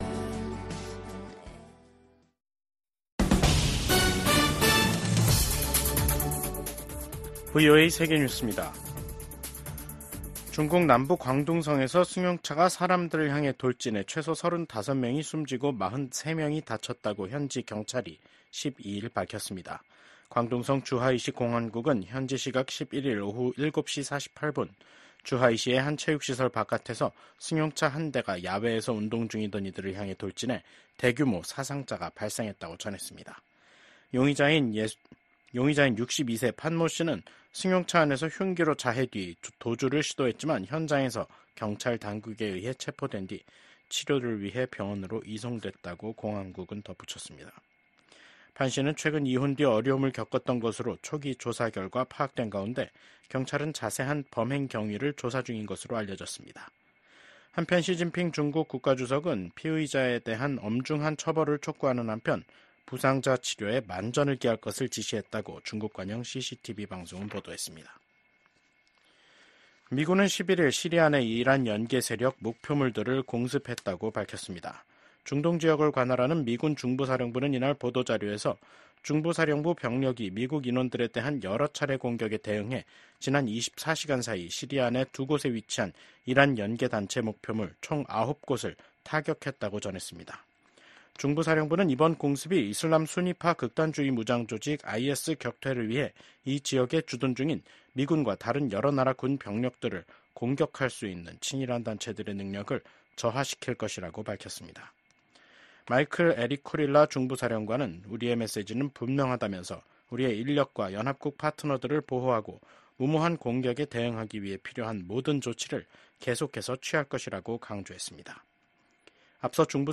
VOA 한국어 간판 뉴스 프로그램 '뉴스 투데이', 2024년 11월 12일 3부 방송입니다. 미국 국무부가 한국에 대한 확장억제 공약을 확인하며, 북한의 핵 공격 시 신속한 응징에 나설 것임을 강조했습니다. 김정은 북한 국무위원장이 러시아와의 관계를 군사동맹 수준으로 끌어올린 북러 조약에 서명하고 양국이 비준 절차를 마무리함에 따라 북한의 러시아 파병 등이 공식화, 본격화할 것이라는 전망이 나옵니다.